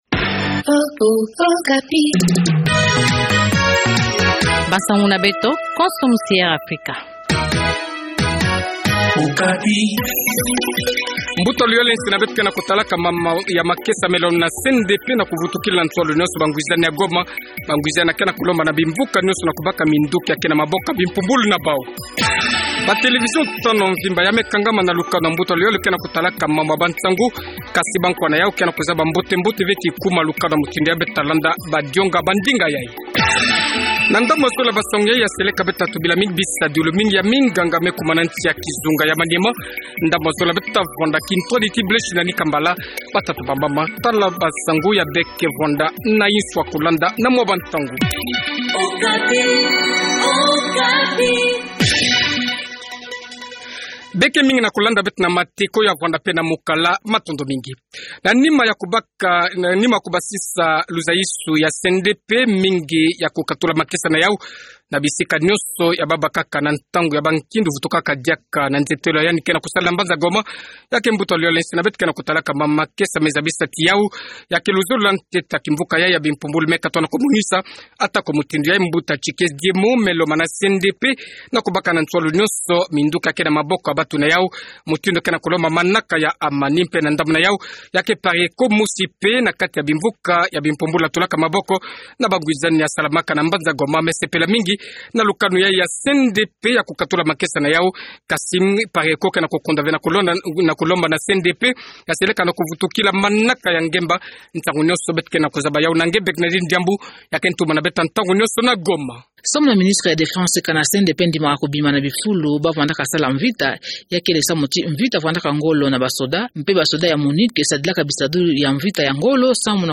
Journal Kikongo Soir